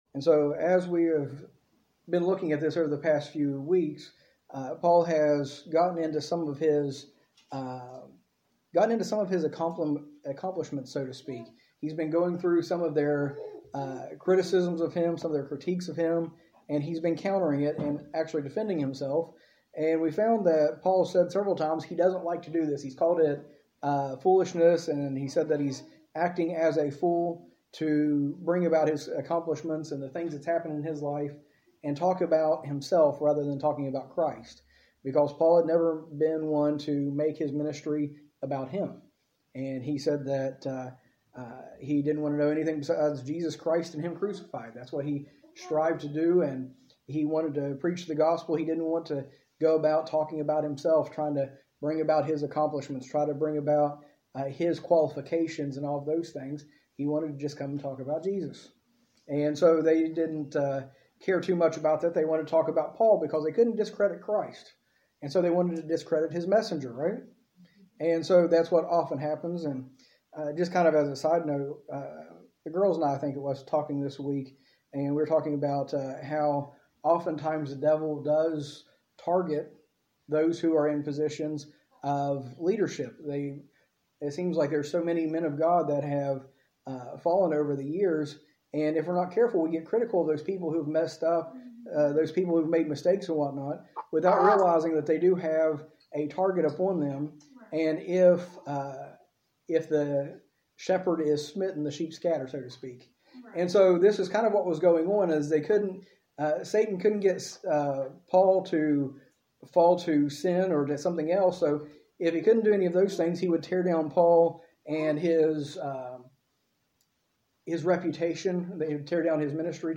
A message from the series "2 Corinthians."